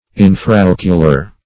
Definition of infraocular. What does infraocular mean? Meaning of infraocular. infraocular synonyms, pronunciation, spelling and more from Free Dictionary.
infraocular.mp3